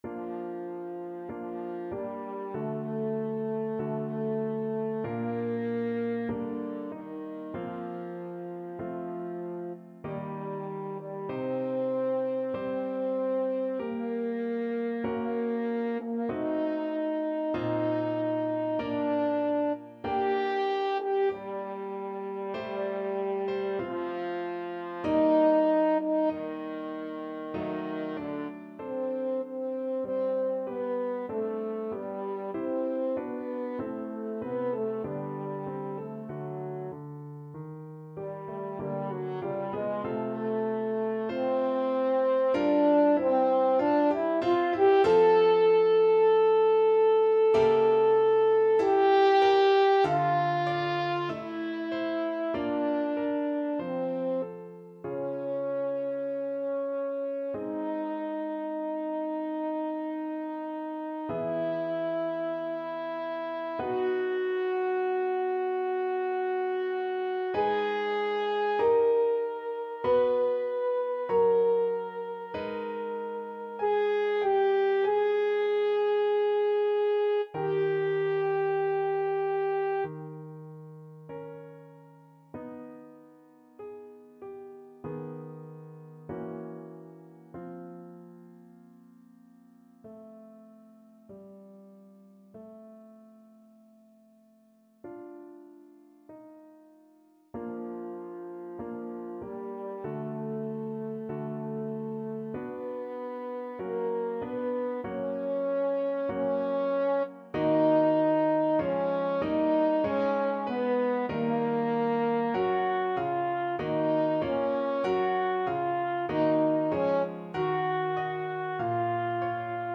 2/2 (View more 2/2 Music)
~ = 96 Alla breve. Weihevoll.
Classical (View more Classical French Horn Music)